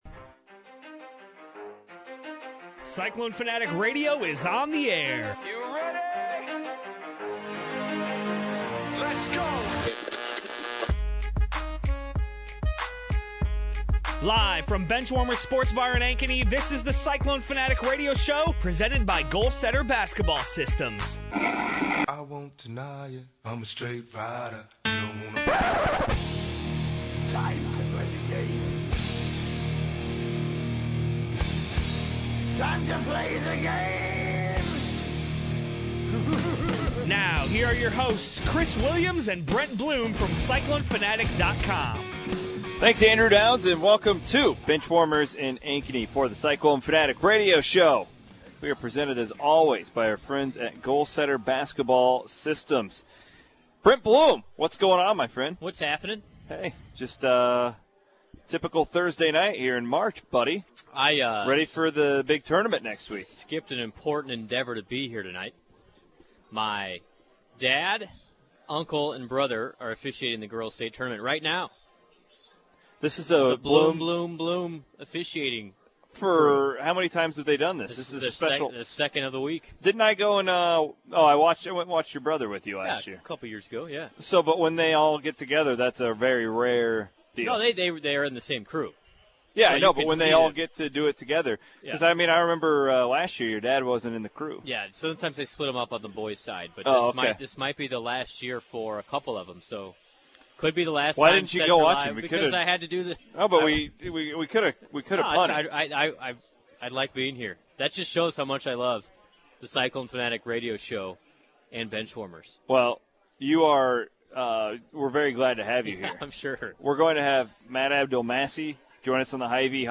A rundown of Thursday night’s Cyclone Fanatic Radio Show that is powered by GoalSetter Basketball Systems, live from BenchWarmers in Ankeny.